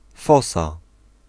Ääntäminen
Ääntäminen Tuntematon aksentti: IPA: /ˈfɔsa/ Haettu sana löytyi näillä lähdekielillä: puola Käännös 1. fosa {f} 2. foso {m} Suku: f .